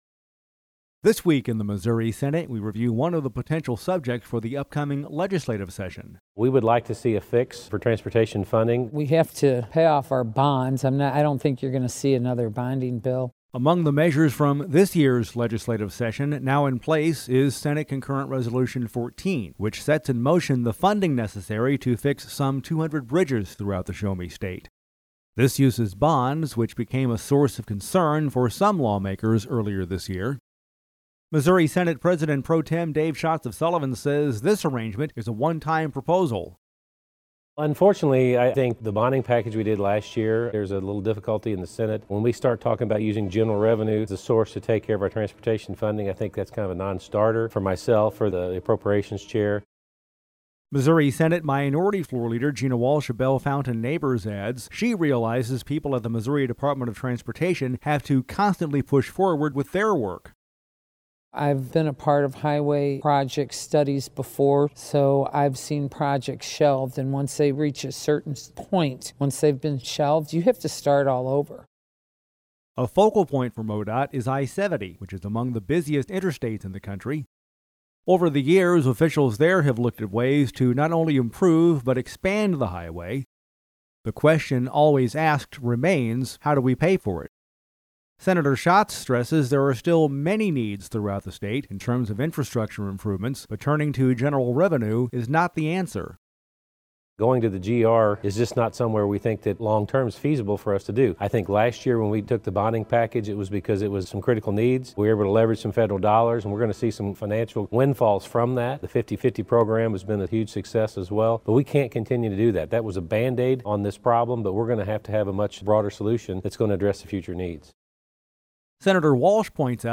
Dec. 20: This Week in the Missouri Senate reviews one of the issues that could be discussed during next year’s legislative session: transportation. We’ve included actualities from Missouri Senate President Pro Tem Dave Schatz, R-Sullivan, and Missouri Senate Minority Floor Leader Gina Walsh, D-Bellefontaine Neighbors, in this feature report